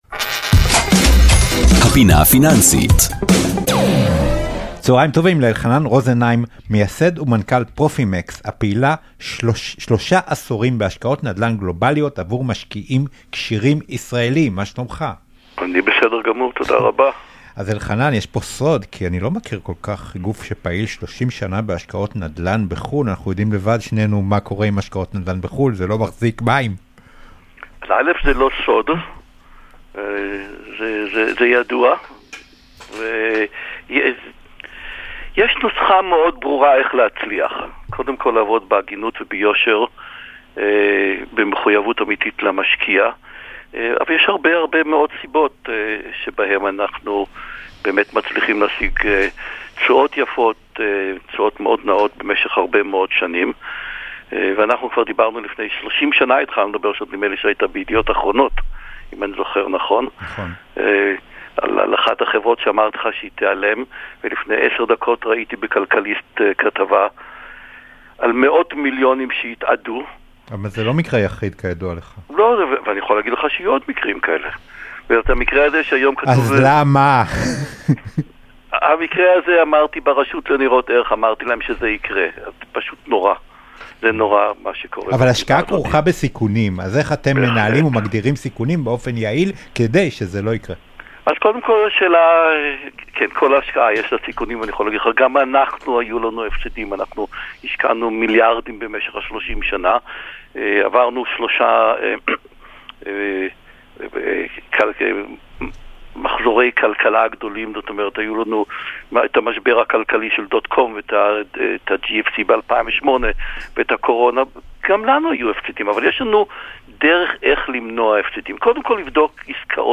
בראיון